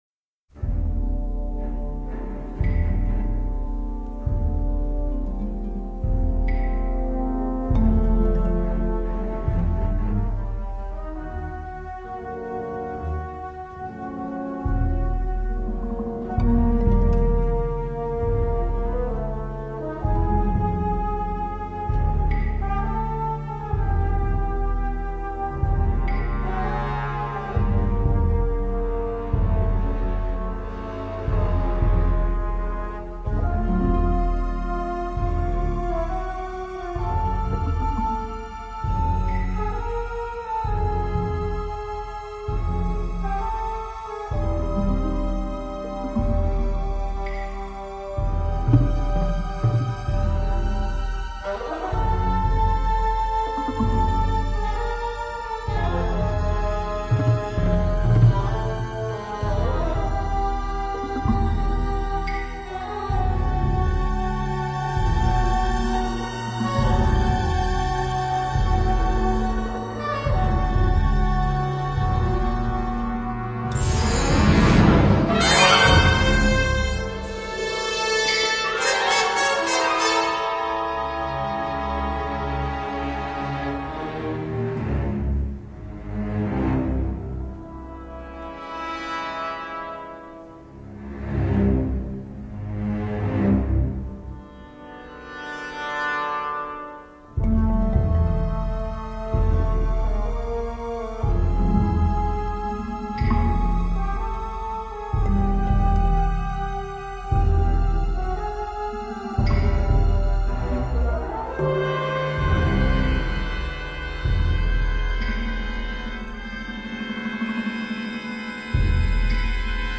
Soundtrack, Classical